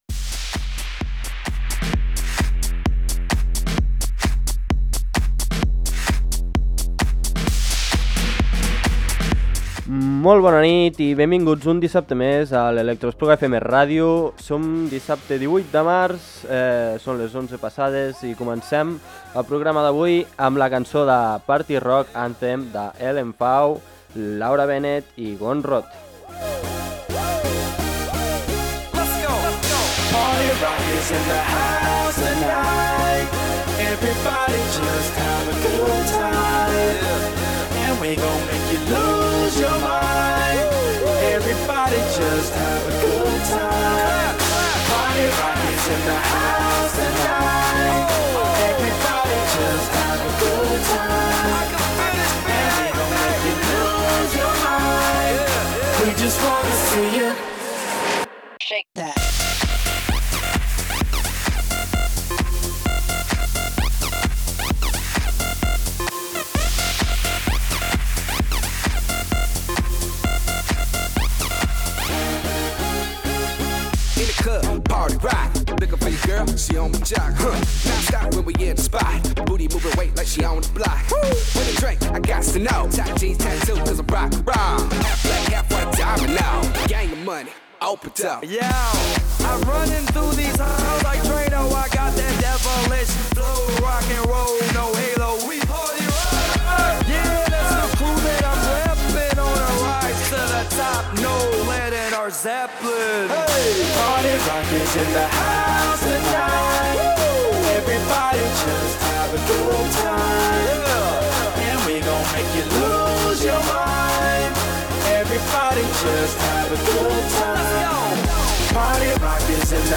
música electrònica